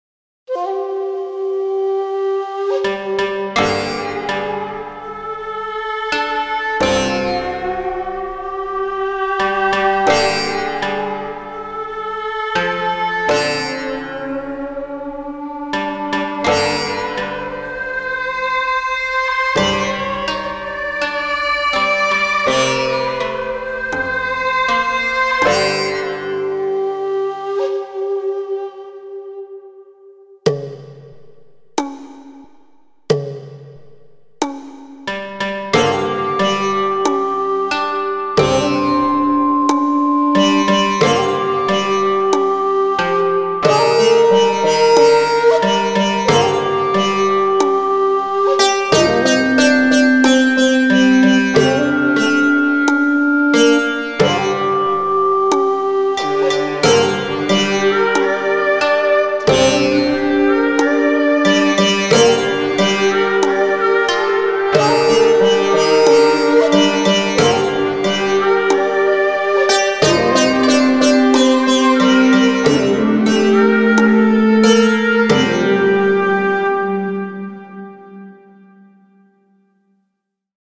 Folk song, U.S.A.
Japanese Version – Karaoke